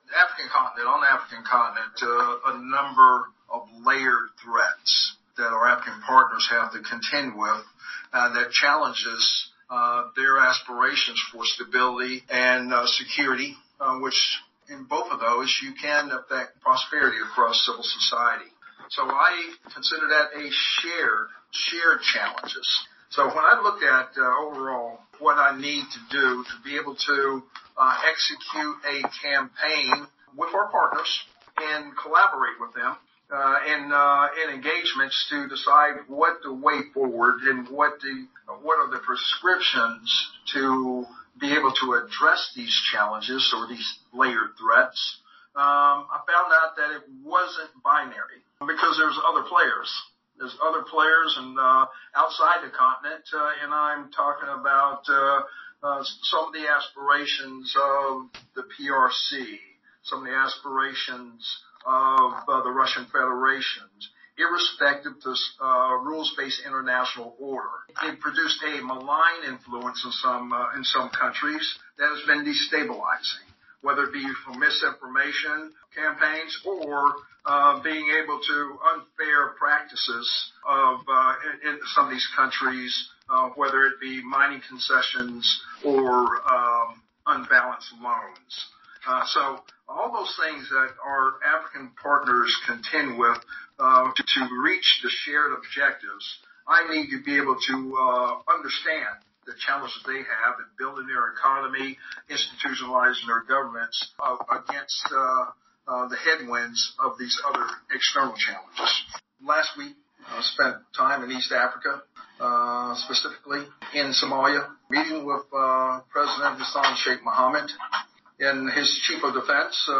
He spoke one-on-one with Zimbabwe-born journalists